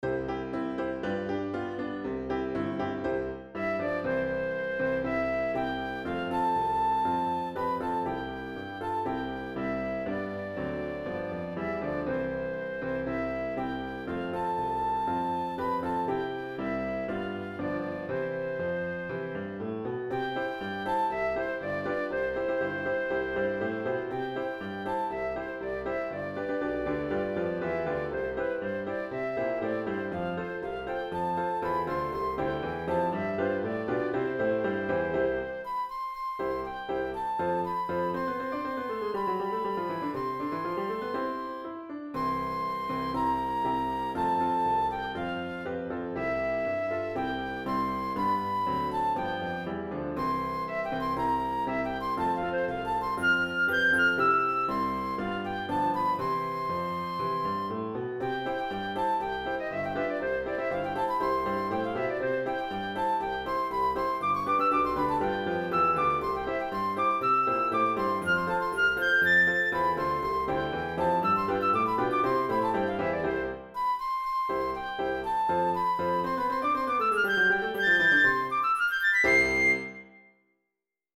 It starts off like O WHEN THE SIANTS GO MARCHING IN except it sounds upside-down. The chorus is a different melody though.
HYMN MUSIC; INSPIRATIONAL MUSIC